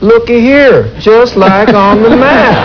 Lookee here, just like on the map (with affected accent).